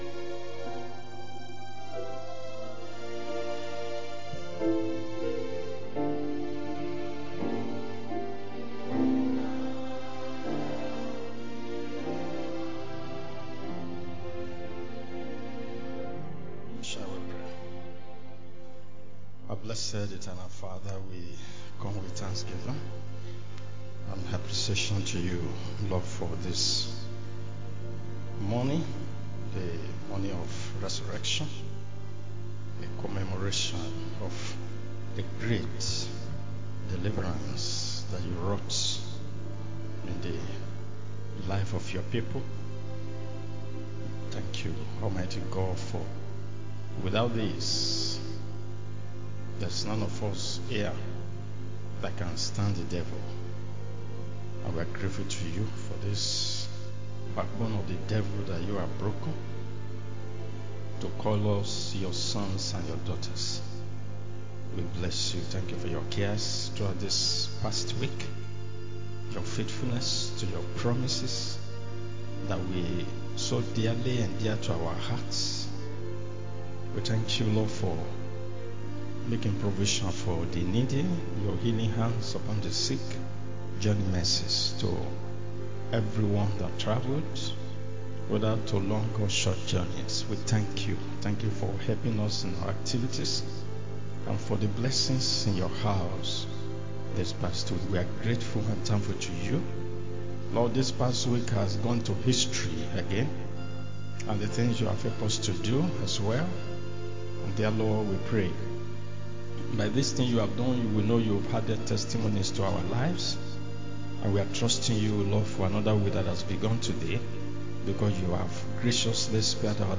Sunday School Class